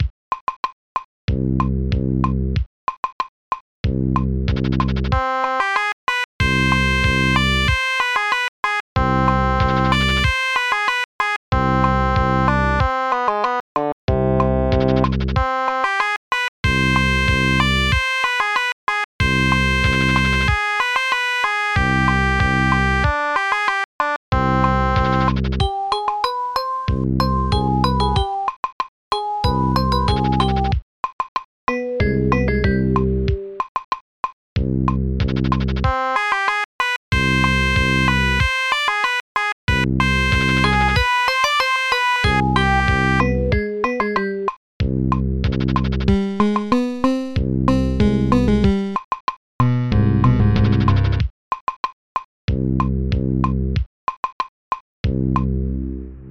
Tracker DSIK DSM-format
Bass Drum 2 Rim Shot 1 Fretless Bass Cabasa Oboe 1 Acoustic Guitar Woodblock 1 Celesta 2